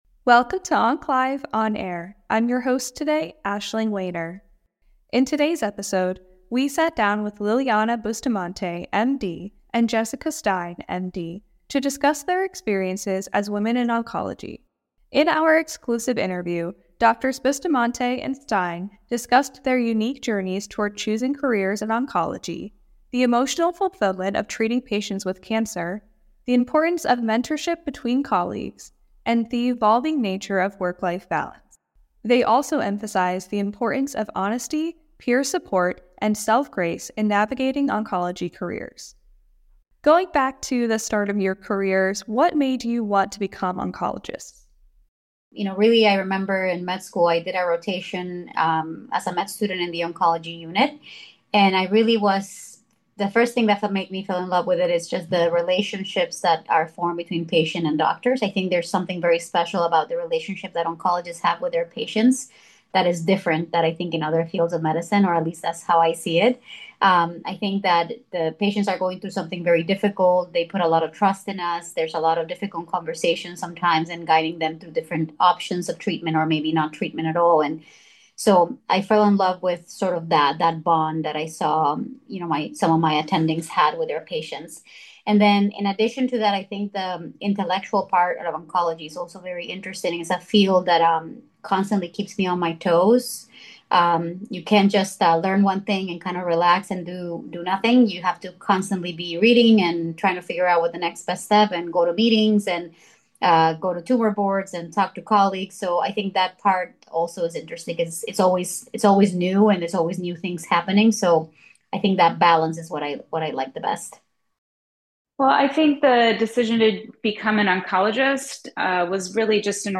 In our exclusive interview